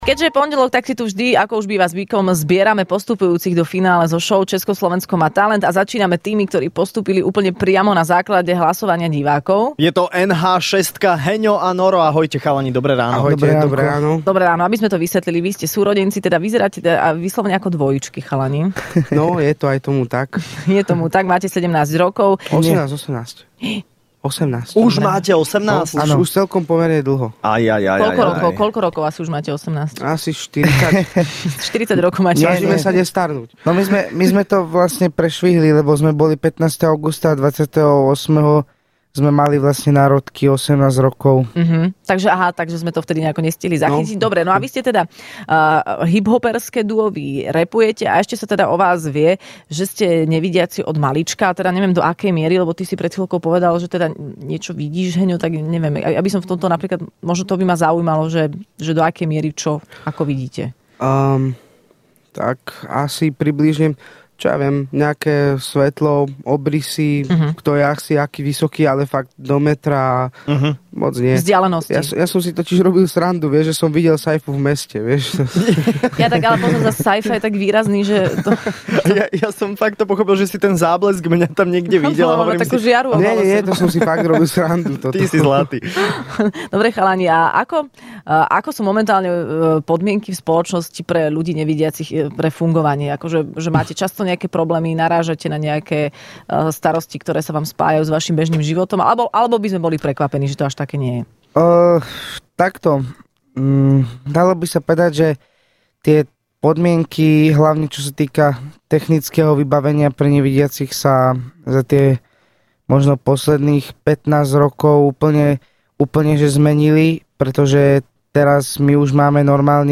V Rannej šou boli hosťami finalisti šou Česko - Slovensko má talent ale okrem nich prišla aj Zuzka Smatanová...
ČSMT I: Rappujúce nevidiace dvojičky z NH6 prezradili čo ich zatial v šou potešilo najviac a ako začínali s rappom...